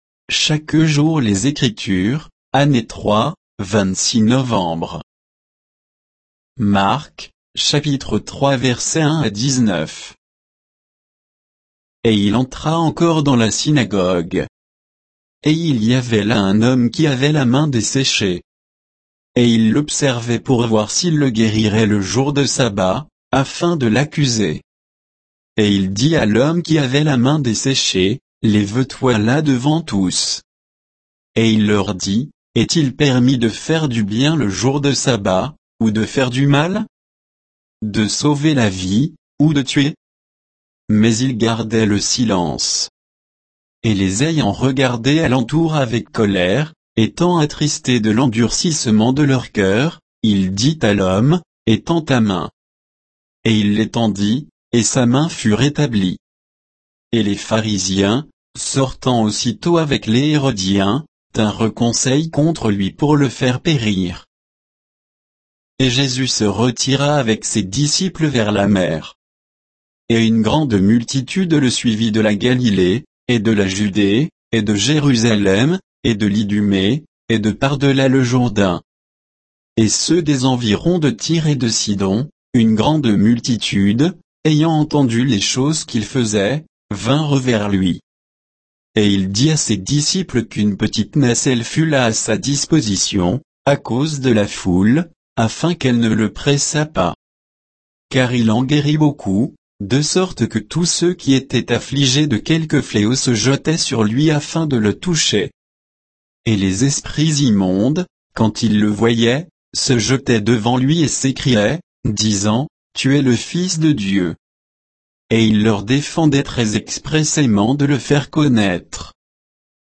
Accueil / Chaque jour les Écritures / Marc [4'31] Marc 3, 1 à 19 [ Chaque jour les Écritures, année 3, 26 novembre ] Une seconde guérison a lieu dans la synagogue de Capernaüm et c’est de nouveau un jour de sabbat ( chap. 1, 21 …).